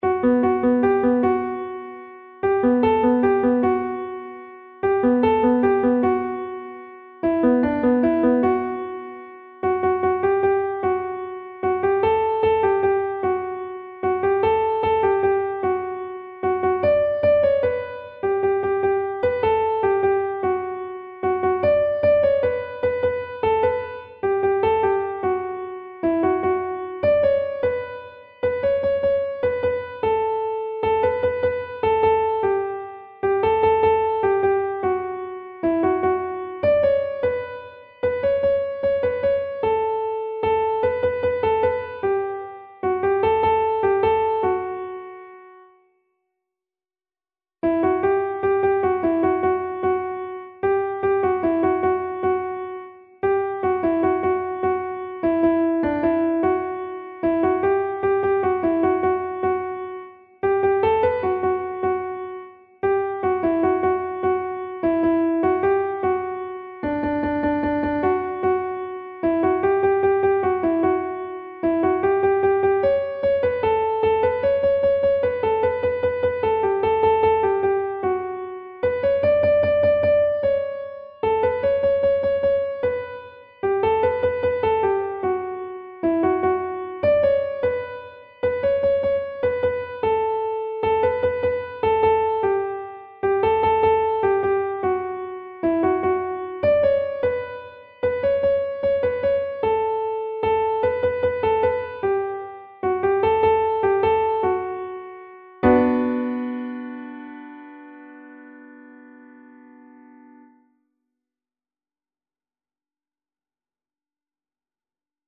نت کیبورد